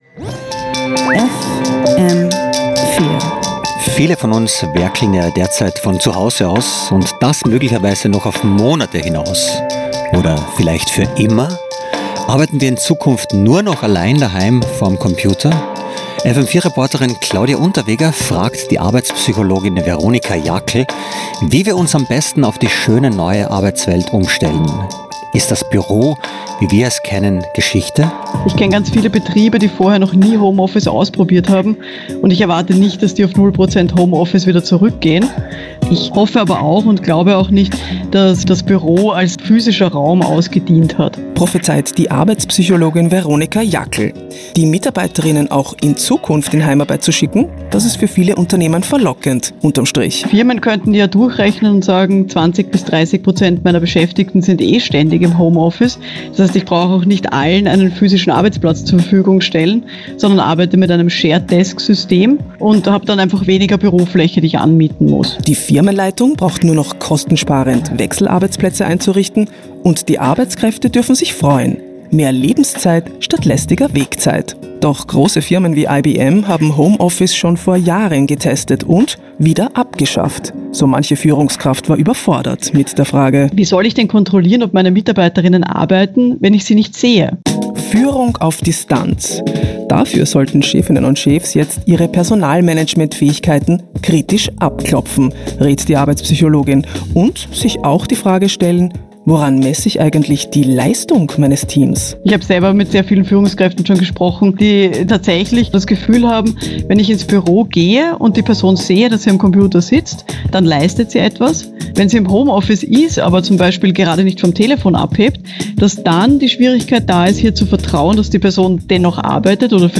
Radiobeitrag vom 02. Mai 2020